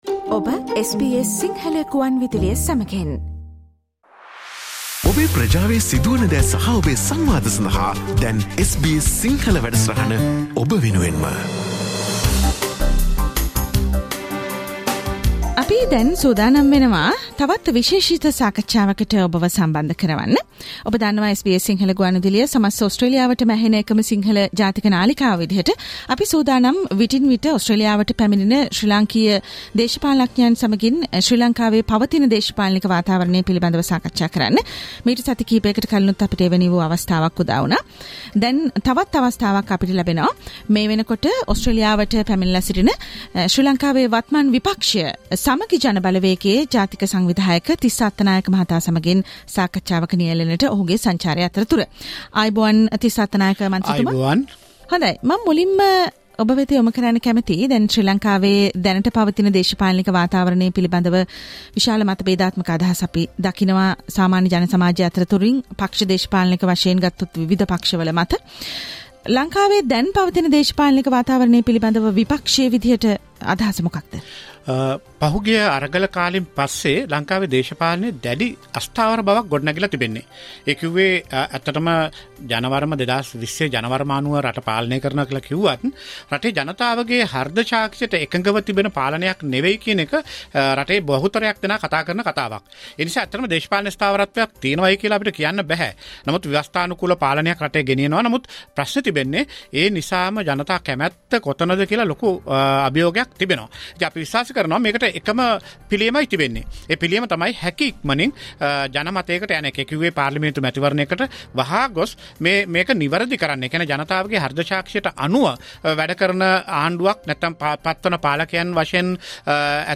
In an interview with the national organiser of the Samagi Jana Balawegaya, the opposition of Sri Lanka, MP Tissa Attanayake speaks to the SBS Sinhala Radio about the current political situation in Sri Lanka during his visit to Australia...
at the SBS Radio Melbourne studios